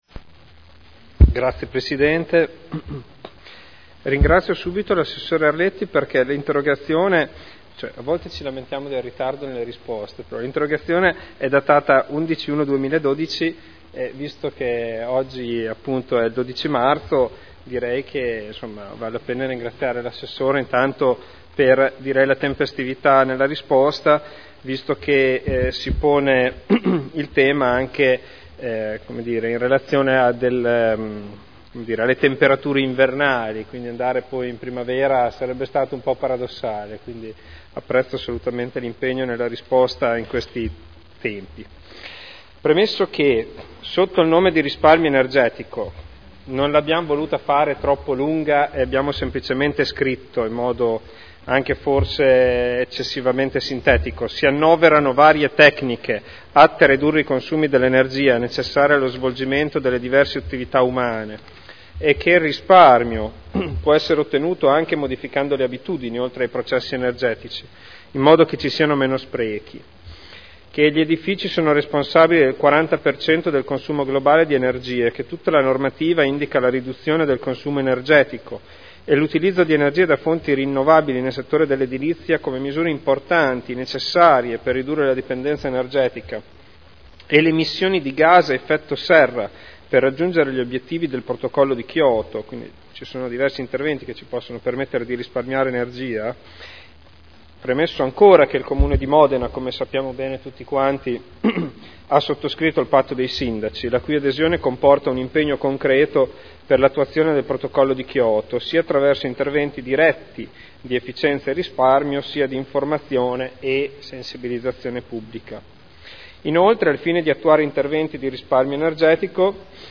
Seduta del 12/03/2012. Interrogazione dei consiglieri Ricci (Sinistra per Modena) e Trande (P.D.) avente per oggetto: “Risparmio energetico” – Primo firmatario consigliere Ricci (presentata l’11 gennaio 2012 - in trattazione il 12.3.2012)